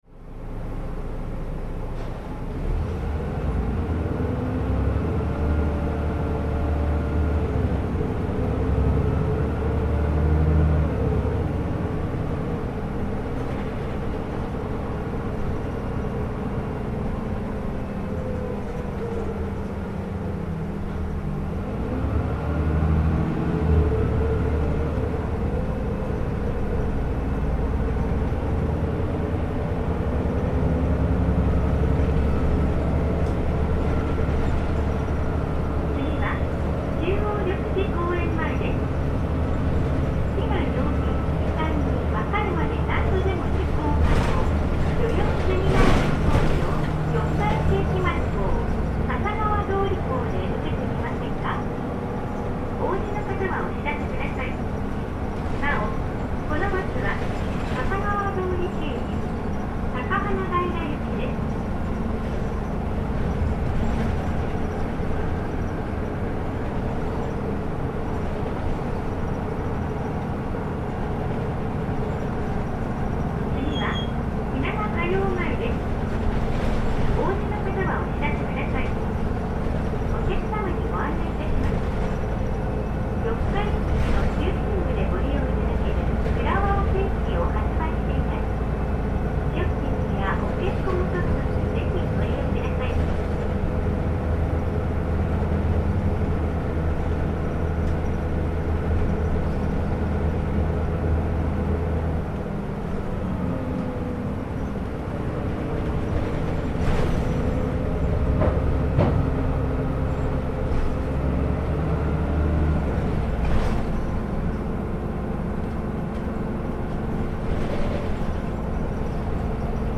また、三重交通では初のオートマ車なので、変速音が新鮮！！
走行音(2分23秒・2.72MB)